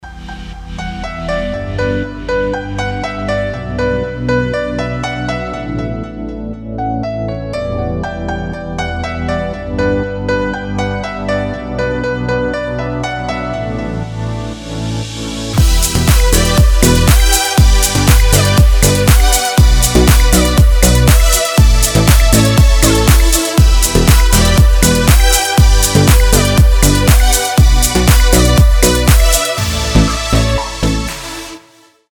• Качество: 320, Stereo
deep house
без слов
красивая мелодия
нарастающие
пианино
deep progressive
Шикарная клавишная мелодия для будильника или звонка